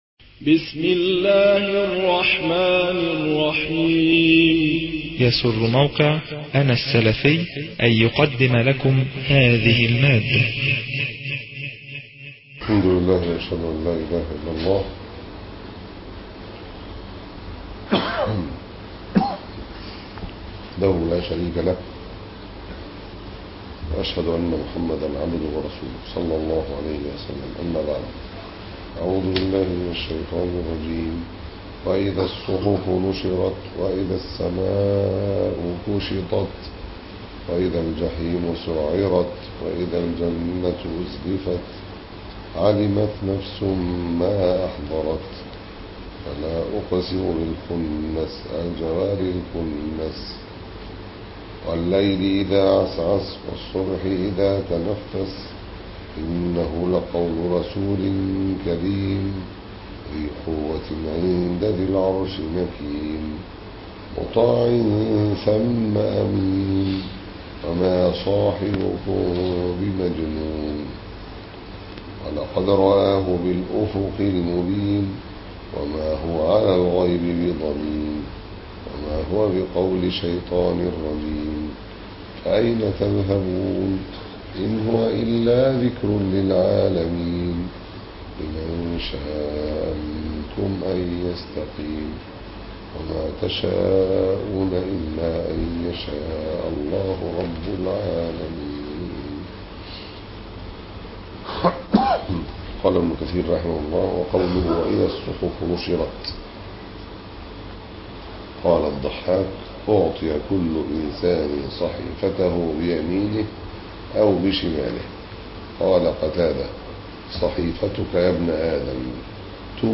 007- تفسير بن كثير - سورة التكوير (3) (معتكف 1433